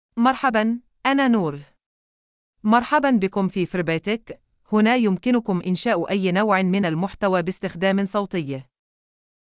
FemaleArabic (Kuwait)
NouraFemale Arabic AI voice
Noura is a female AI voice for Arabic (Kuwait).
Voice: NouraGender: FemaleLanguage: Arabic (Kuwait)ID: noura-ar-kw
Voice sample
Listen to Noura's female Arabic voice.
Noura delivers clear pronunciation with authentic Kuwait Arabic intonation, making your content sound professionally produced.